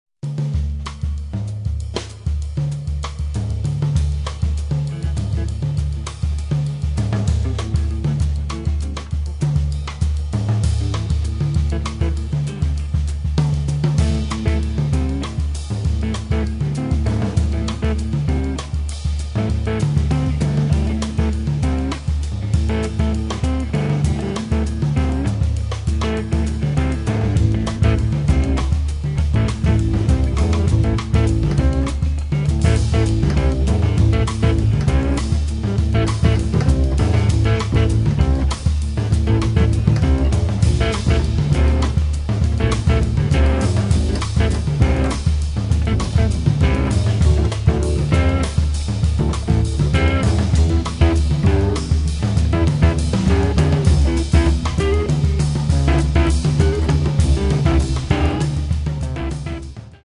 drums
guitar
piano
double bass